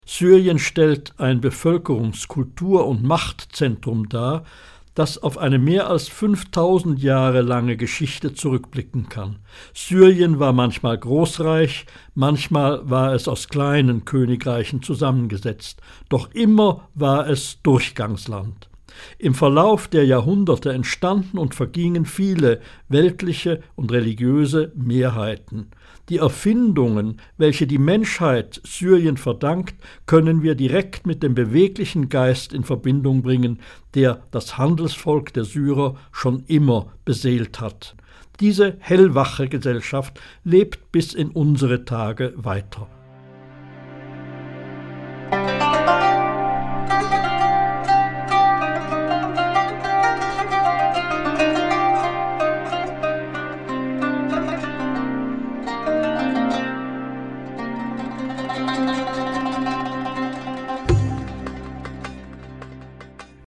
Hörbuch Syrien